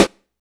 Hat (69).wav